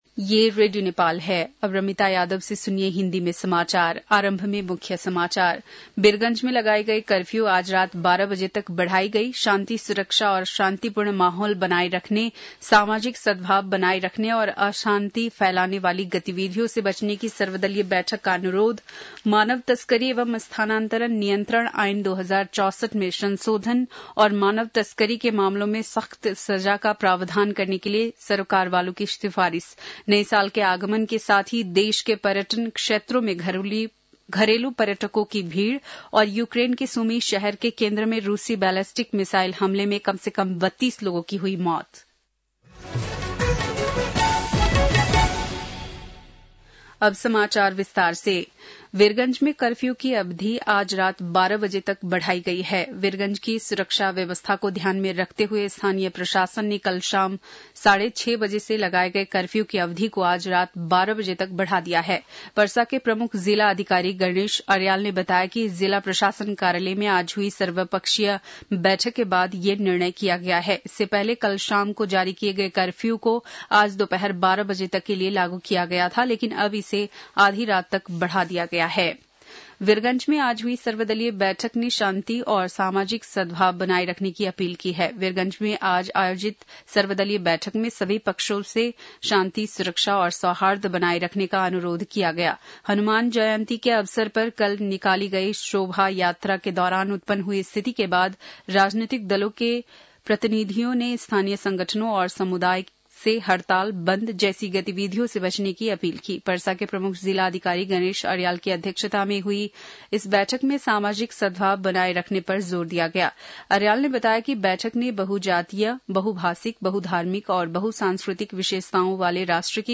बेलुकी १० बजेको हिन्दी समाचार : ३१ चैत , २०८१